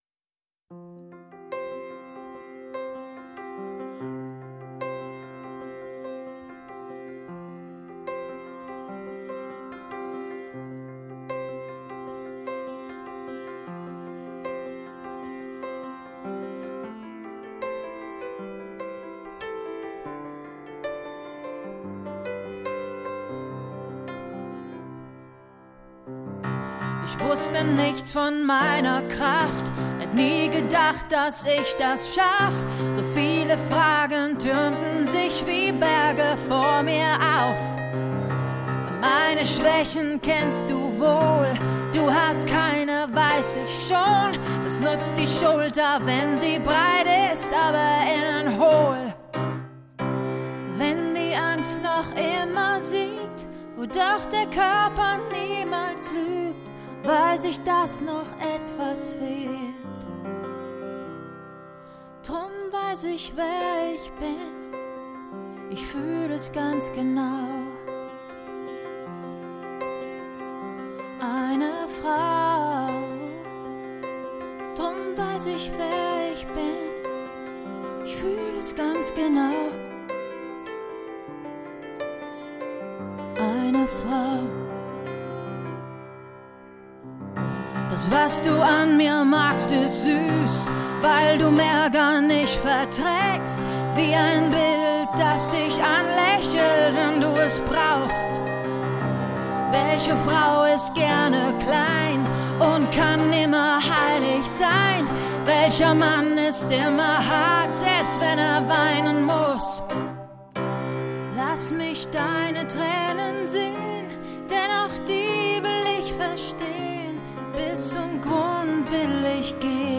Genre: Contemporary Blues.